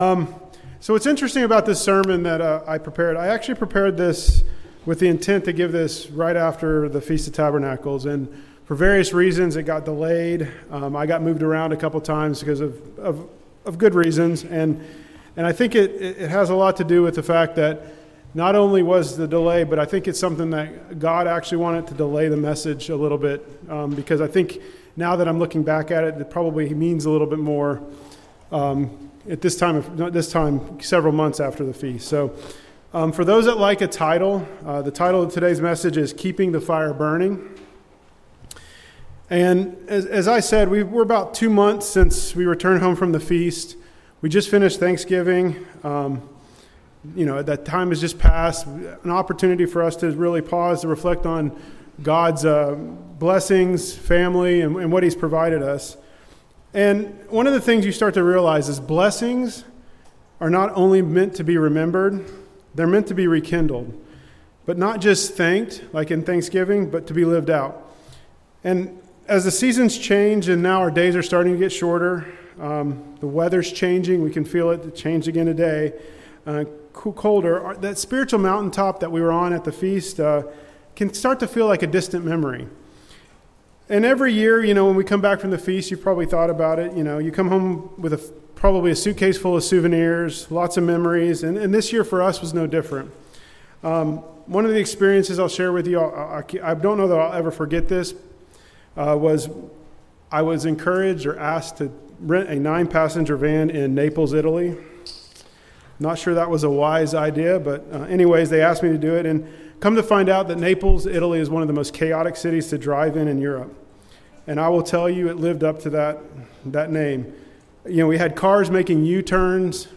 This sermon focused on Keeping the Fire Burning when we return from the Feast of Tabernacles and go through the long, dark winter months. We explore how keeping the fire burning for God helps us get through trials that might arise.
Given in Jacksonville, FL